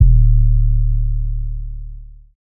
TS 808 2.wav